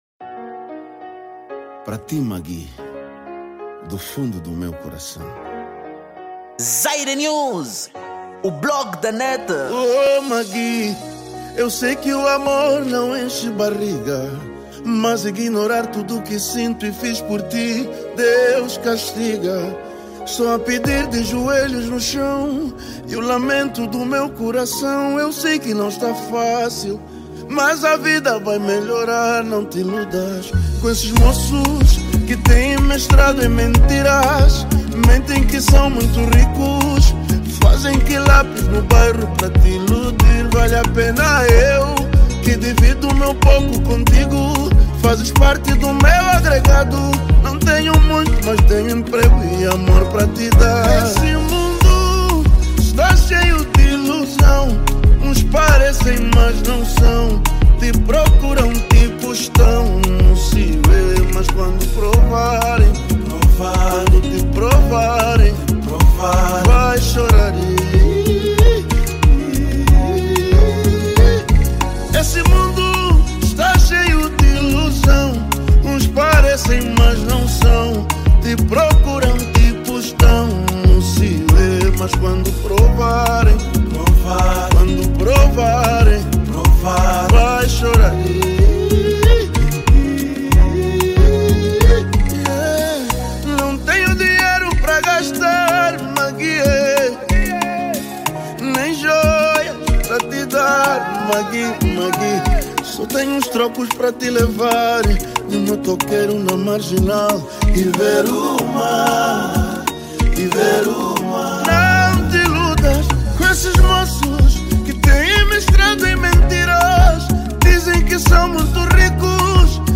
Género: Zouk